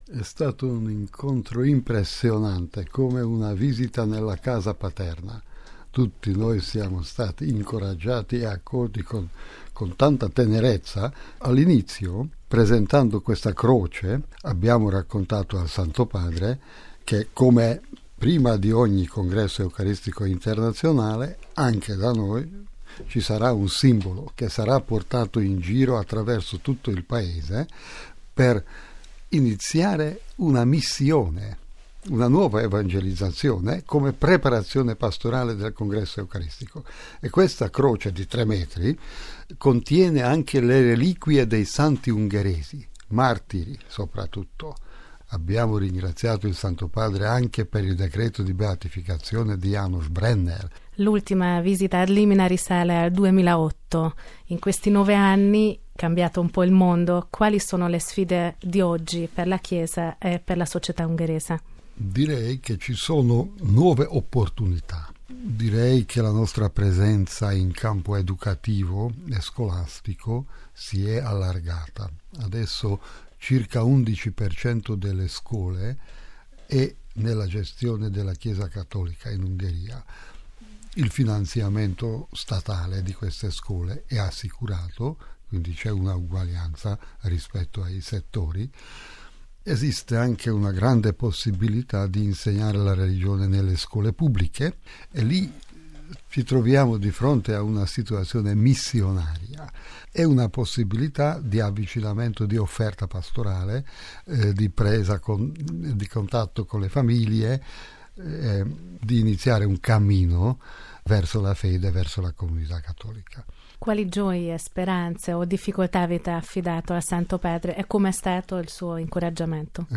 Il cardinale Péter Erdő , arcivescovo di Esztergom-Budapest, ha raccontato i particolari della visita al microfono